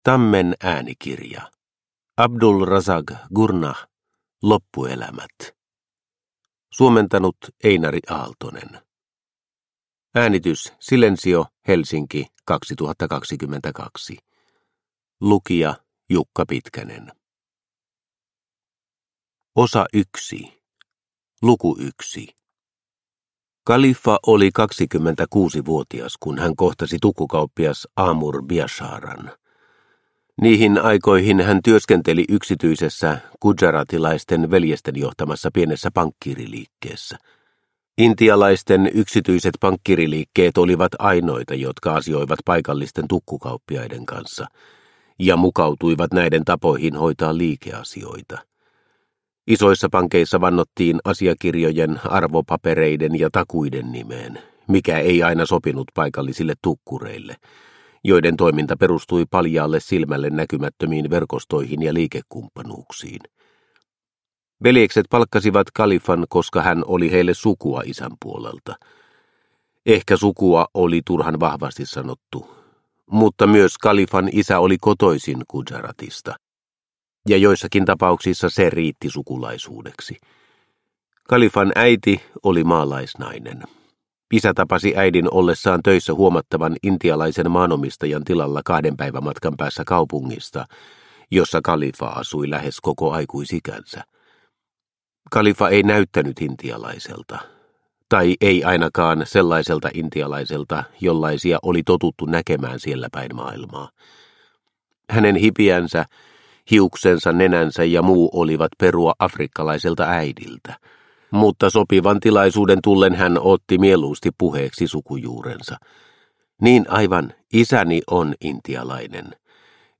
Loppuelämät – Ljudbok – Laddas ner